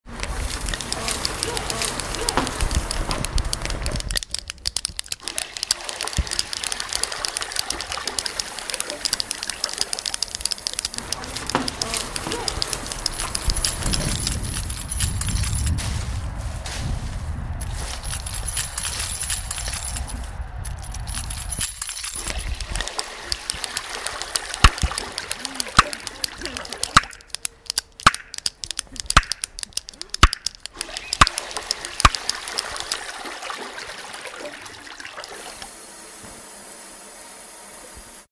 Total Normal Geräuschcollagen
Rund ums Radio war die Redaktion von Total Normal auf Geräusche-Fang und hat die selbst aufgenommenen Geräusche in einer Collage verarbeitet.
Die Schritte auf der Stahlrampe oder das Öffnen des Reisverschlusses nehmen wir im Alltag kaum mehr wahr. Aber als einzelnes Geräusch hört sich das Blätter rascheln oder die Hupe des E-Rollis ziemlich cool an.